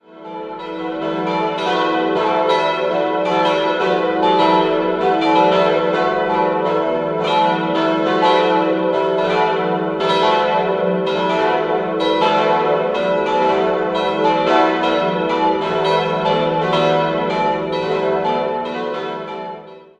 4-stimmiges Gloria-TeDeum-Geläute: e'-fis'-a'-h'